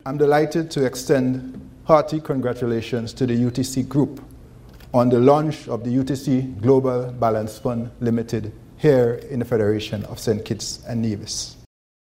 That was Governor of the Eastern Caribbean Central Bank, Mr. Timothy N. J. Antoine. He was giving remarks on the launch of the UTC Global Balanced Fund in the Federation.